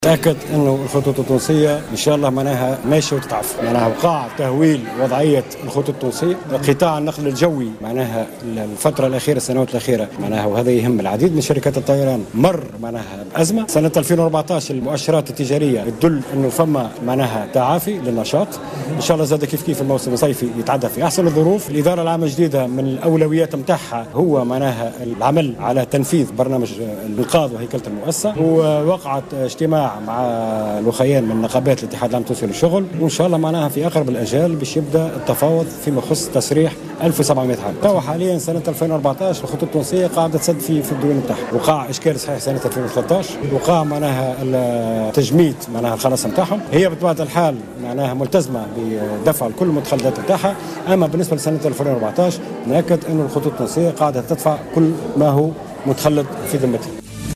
Chiheb Ben Ahmed, ministre du transport, est revenue dans une déclaration accordée à Jawhara FM ce lundi 9 juin 2014, sur la situation de Tunisair.